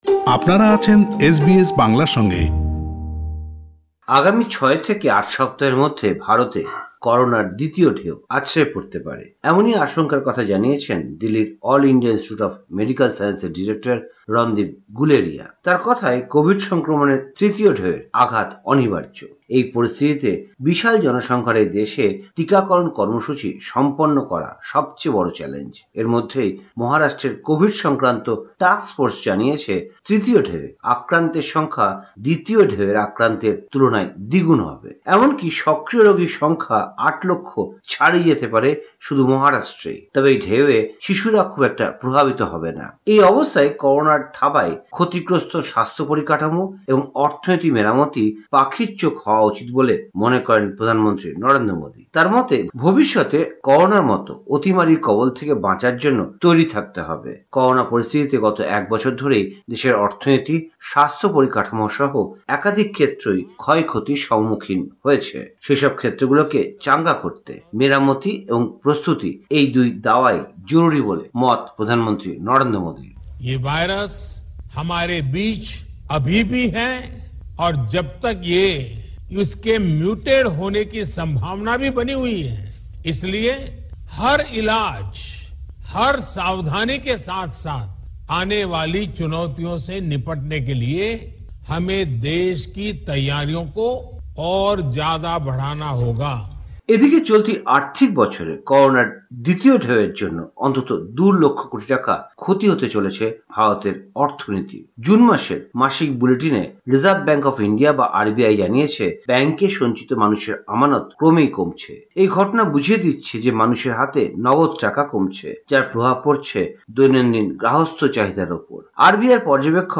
ভারতীয় সংবাদ: ২১ জুন ২০২১